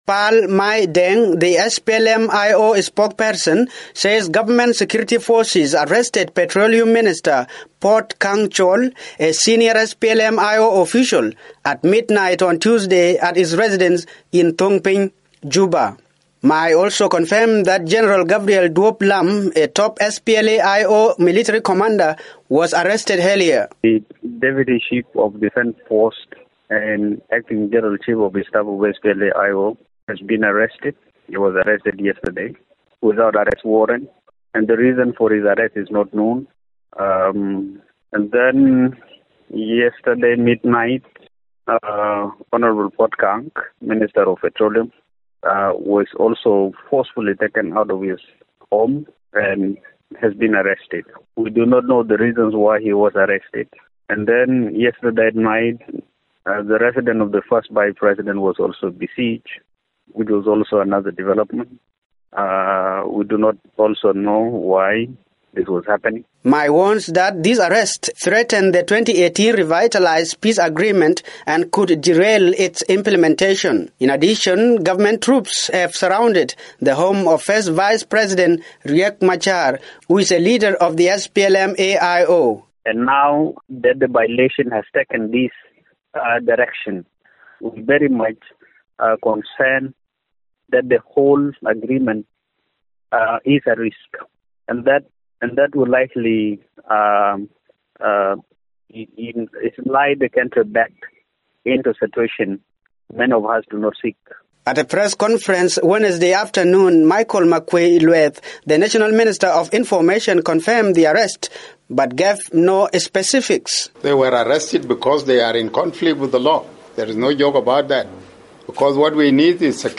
files from Juba